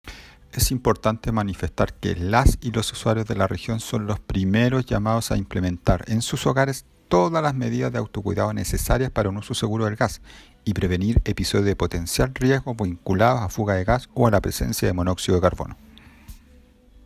Audio: Patricio Velásquez, Director Regional de SEC Valparaíso